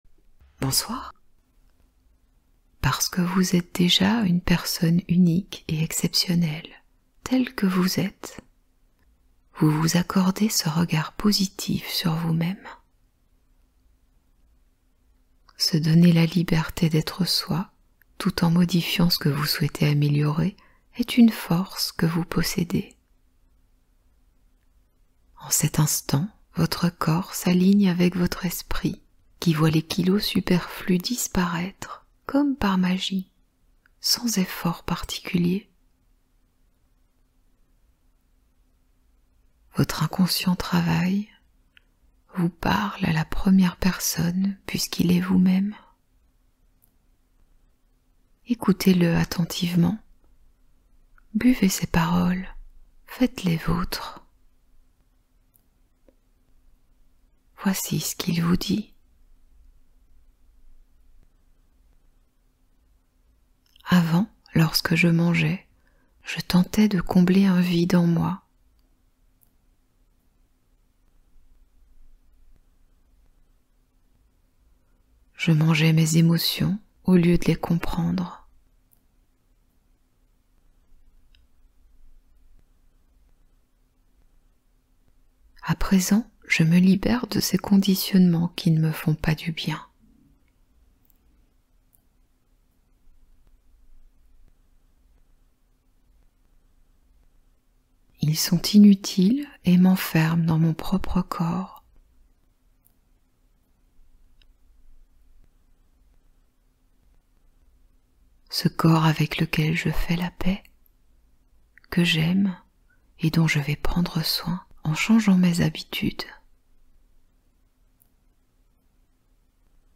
Hypnose 10 minutes : mincir et dormir en reprogrammant l’inconscient